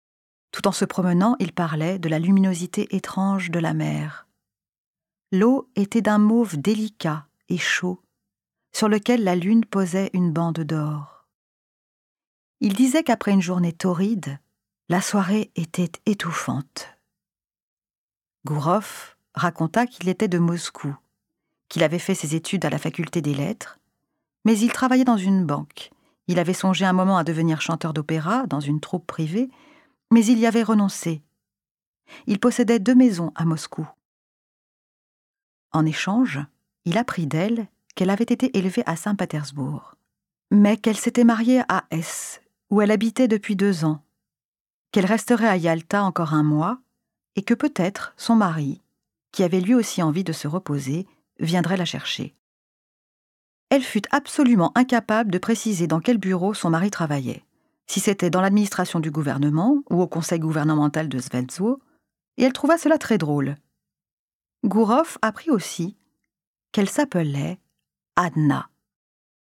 Prix du livre audio des 2018